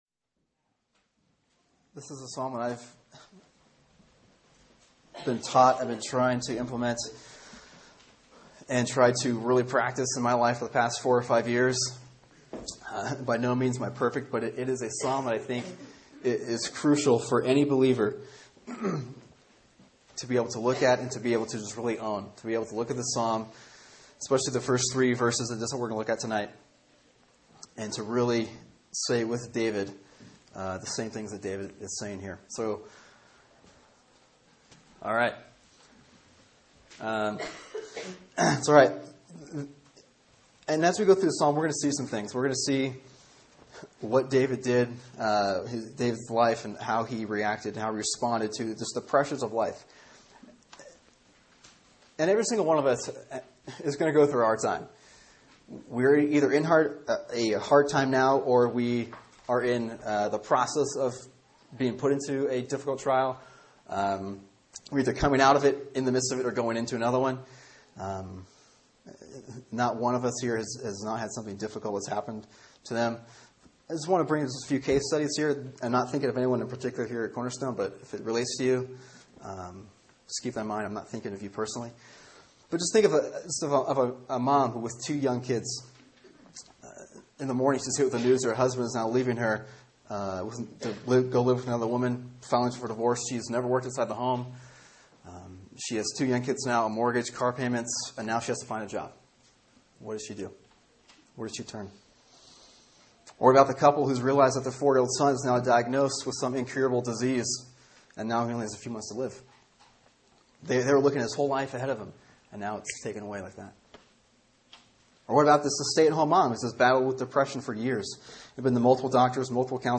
Sermon: Psalm 18:1-3 “Who or What is Your God?”